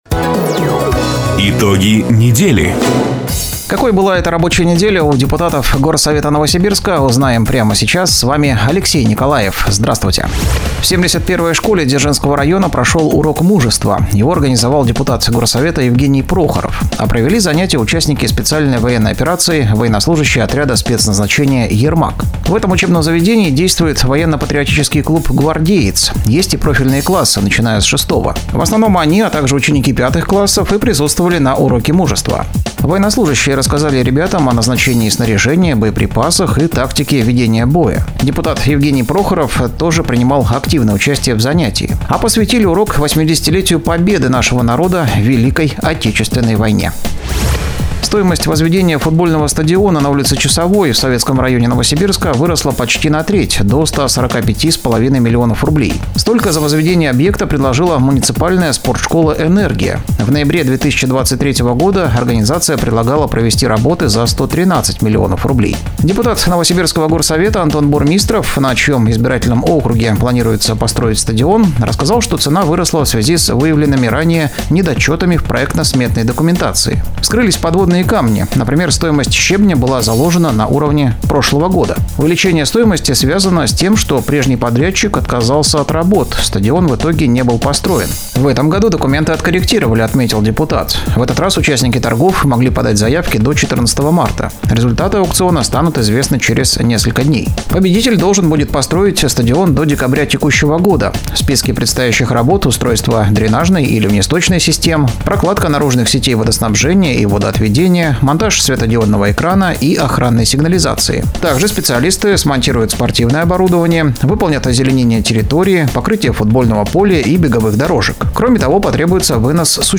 Запись программы "Итоги недели", транслированной радио "Дача" 15 марта 2025 года